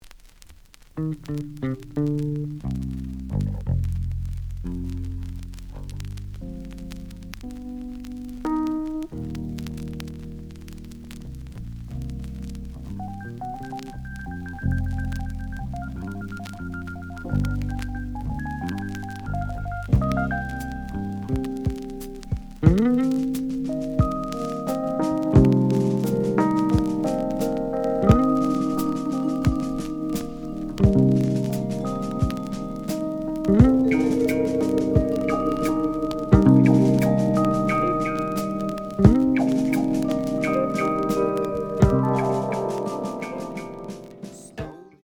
The audio sample is recorded from the actual item.
●Genre: Jazz Funk / Soul Jazz
Looks good, but slight noise on both sides.)